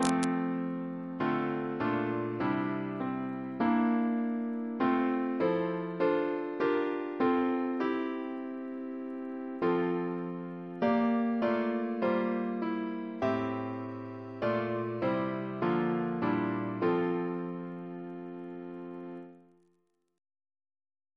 Double chant in F Composer: Alec Wyton (1921-2007), Organist of St. John the Divine Reference psalters: ACP: 47